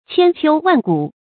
千秋萬古 注音： ㄑㄧㄢ ㄑㄧㄡ ㄨㄢˋ ㄍㄨˇ 讀音讀法： 意思解釋： 猶言千秋萬代，形容歲月長久。